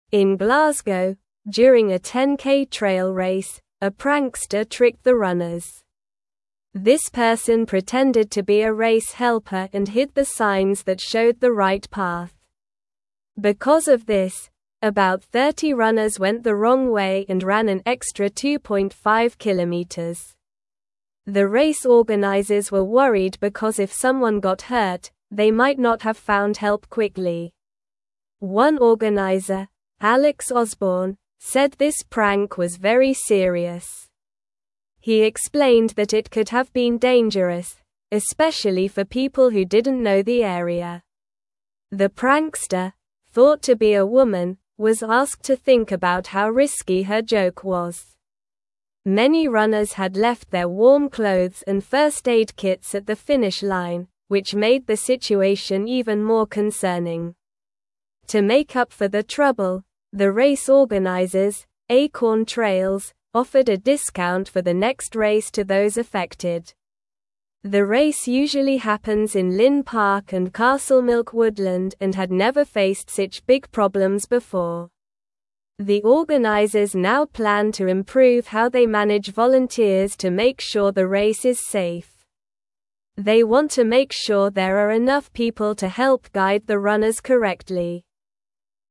Slow
English-Newsroom-Lower-Intermediate-SLOW-Reading-Trickster-Confuses-Runners-in-Glasgow-Race.mp3